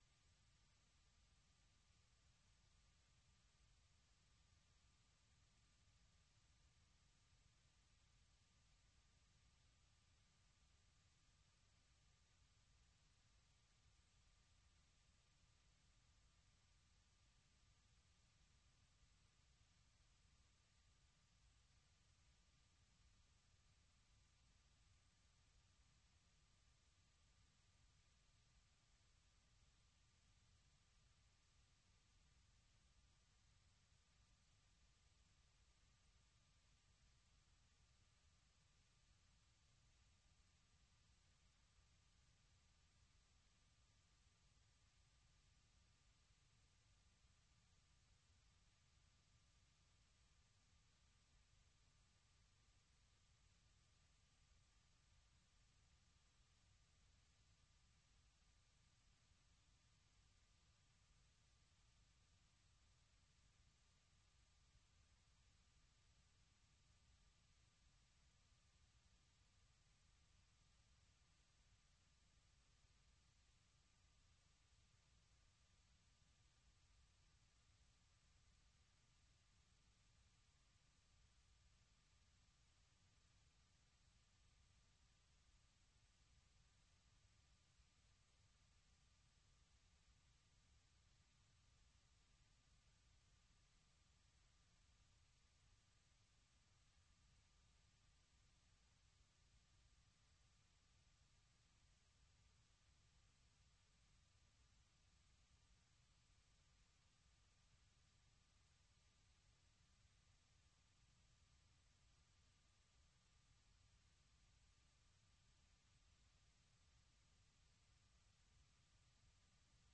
Allocution du président Donald Trump devant la session conjointe du Congrès
Vous suivez sur VOA Afrique l'édition spéciale sur l’allocution du président américain Donald Trump devant une session conjointe du Congrès, suivi de la réponse du Parti démocrate.